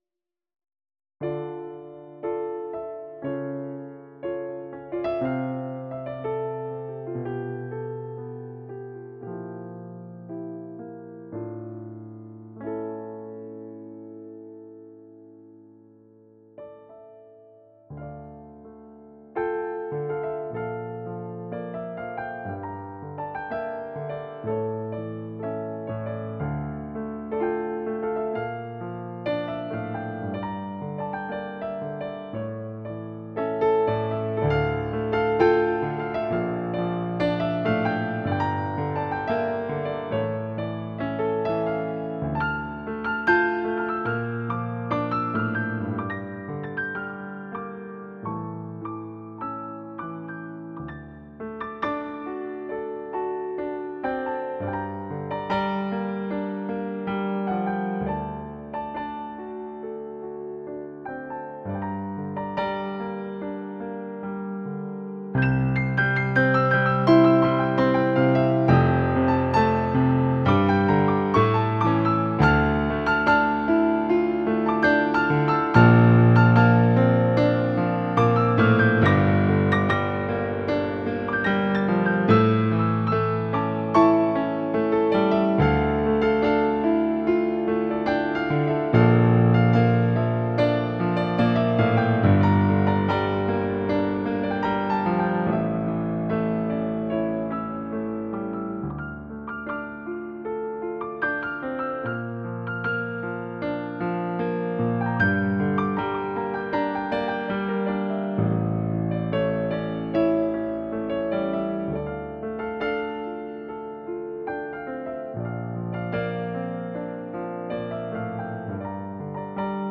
In 'The Secret of Silence' is een stuk die zacht begint met een, onzekere toon wat daarna opbouwt naar een spannende stuk. Het is een intiem, mysterieus en een emotioneel stuk, waarin stilte en spanning belangrijk is.